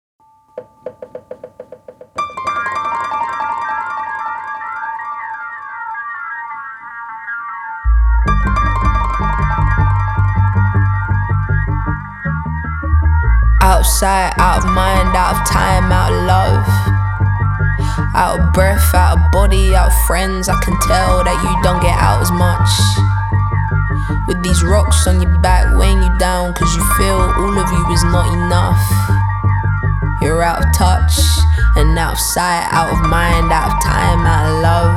Soundtrack Pop
Жанр: Поп музыка / Соундтрэки